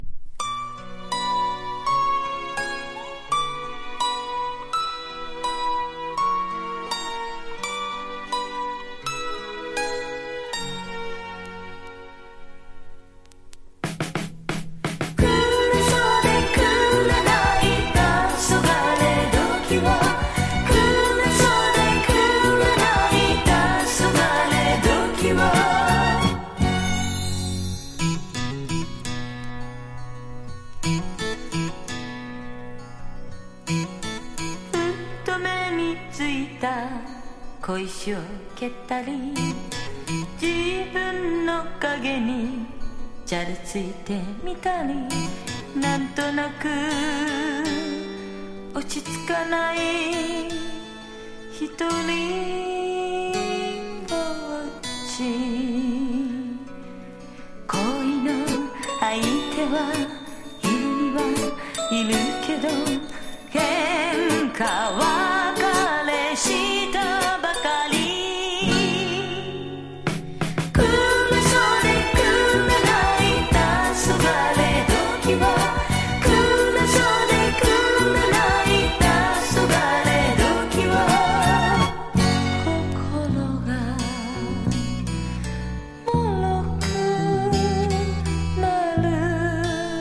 和モノ / ポピュラー# 70-80’S アイドル